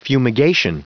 Prononciation du mot fumigation en anglais (fichier audio)
Prononciation du mot : fumigation